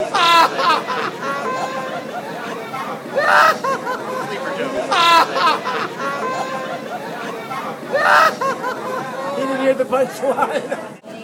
That laugh! (He who laughs, lives!)